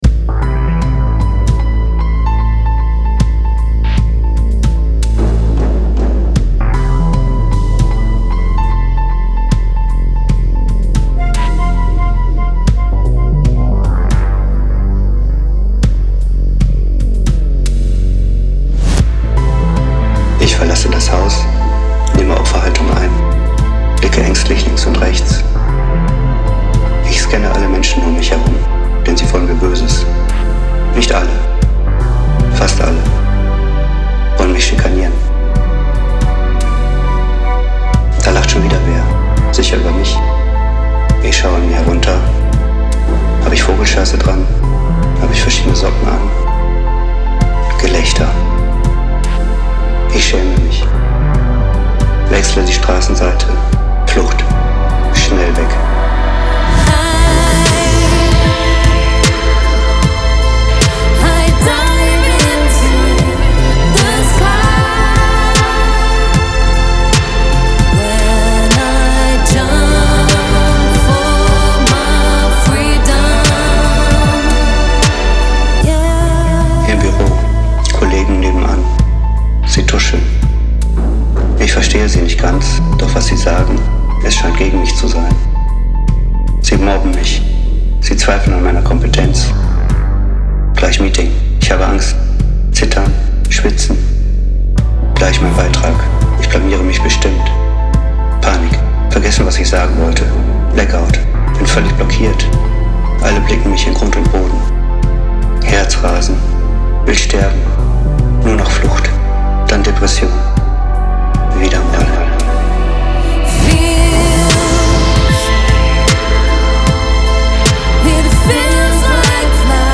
Vocal performance anonymous (chorus / Refrain)